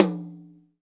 TOM TOM 93.wav